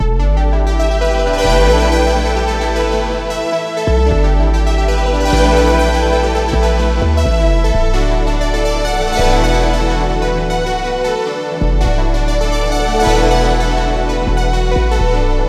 Bounce - A9sus4 and E9sus4 in 2 octaves. Bass loop added in DAW.